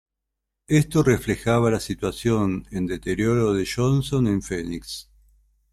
/ˈʝonson/